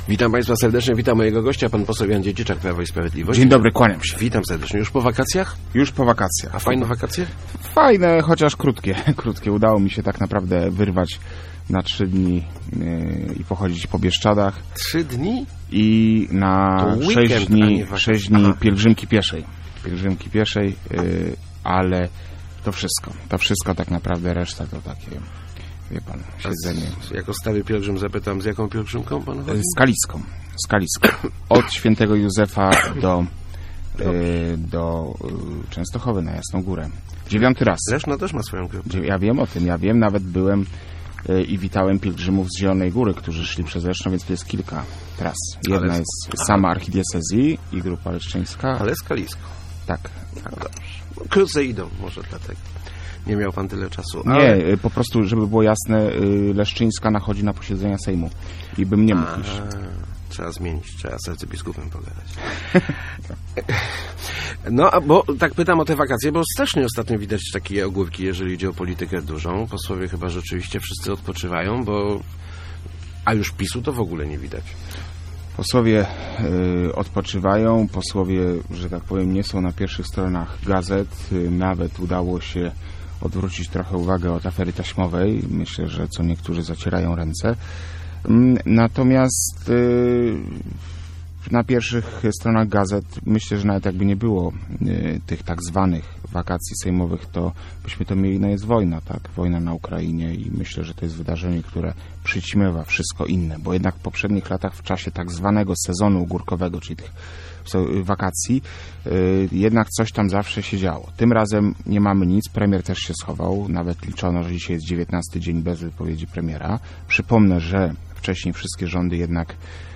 Nasz cel na wybory samorządowe to "odbicie" co najnmniej pięciu sejmików wojewódzkich - mówił w Rozmowach Elki poseł PiS Jan Dziedziczak.